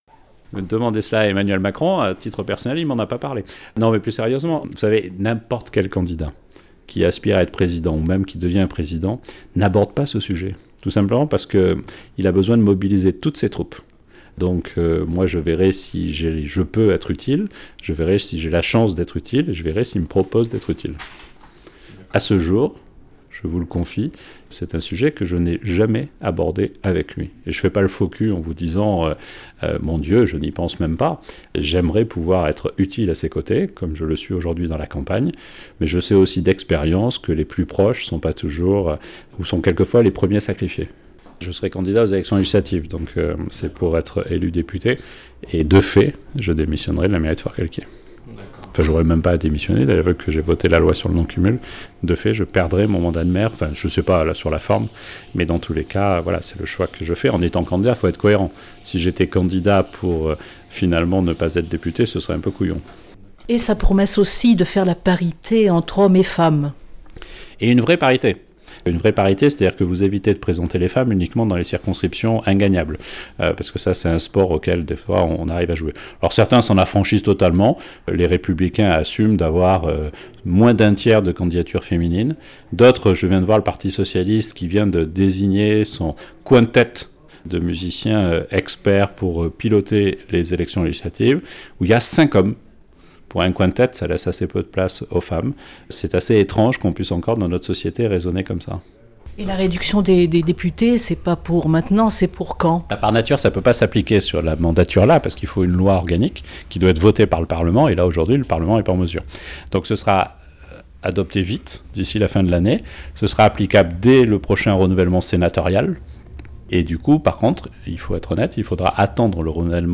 En marge du meeting